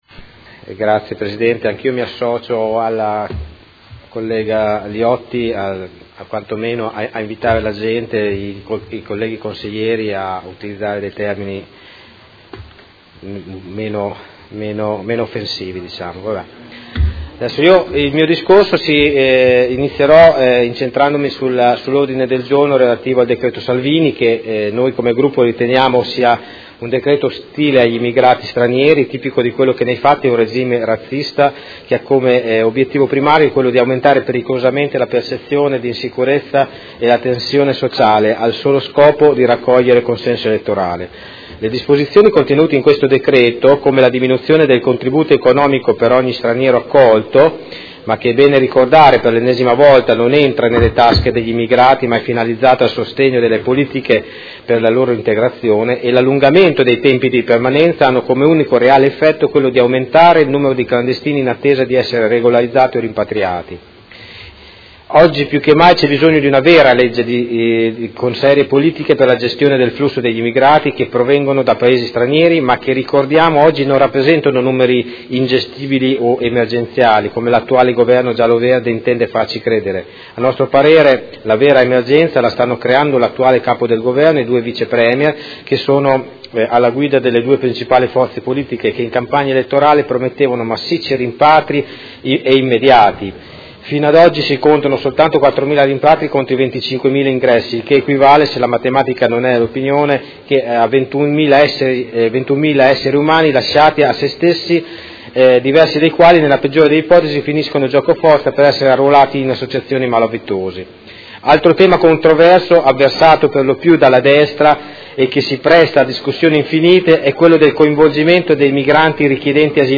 Seduta del 29/11/2018 Dibattito congiunto su Ordine del Giorno Prot. Gen. n.158233 e Ordine del Giorno Prot. Gen. n. 168296 e Ordine del Giorno Prot. Gen. n. 175105